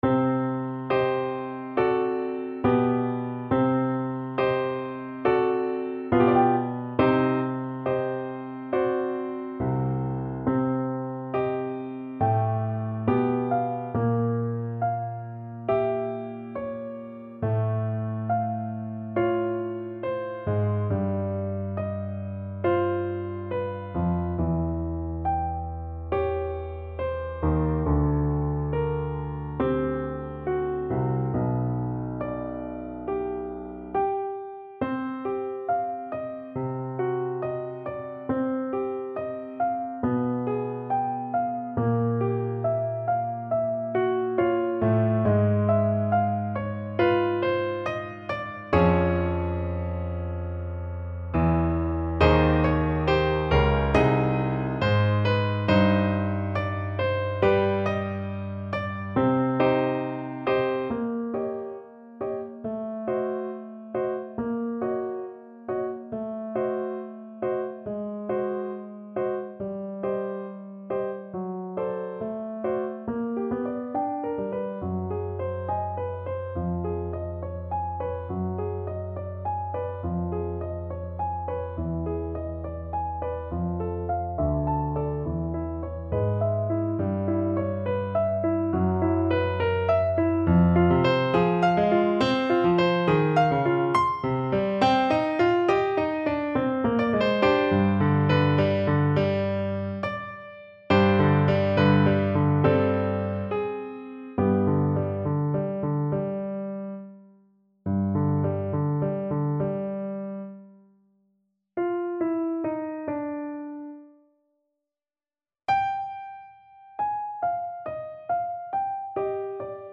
~ = 69 Andante con duolo
Classical (View more Classical Trombone Music)